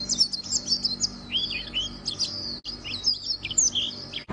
暗绿绣眼鸟鸣声